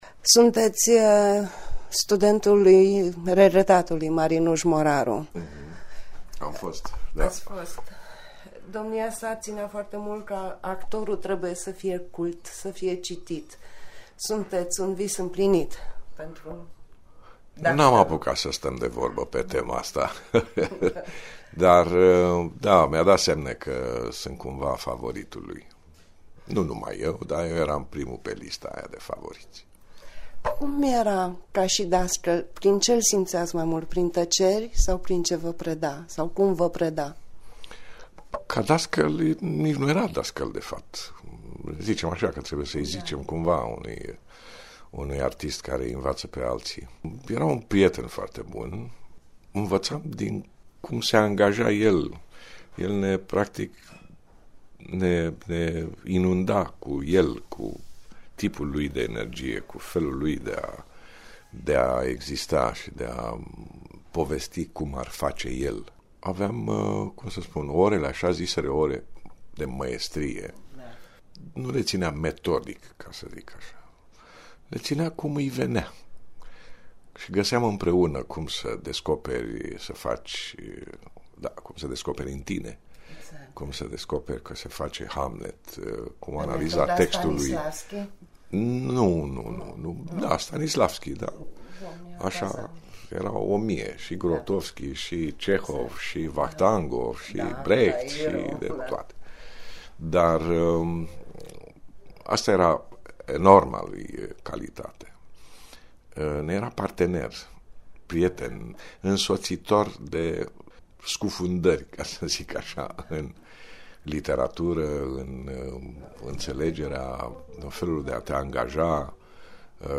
Cu emoţie, fostul său student Marcel Iureş a mărturisit în exclusivitate pentru Radio România Reşiţa:
marcel-iures-despre-marin-moraru.mp3